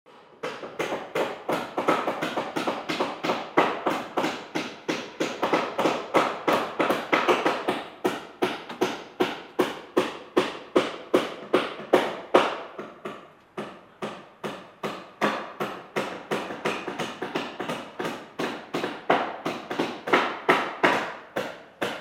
Здесь вы найдете шумы инструментов, техники и обстановки строительной площадки.
Шумы при строительстве частного дома или дачи рабочей бригадой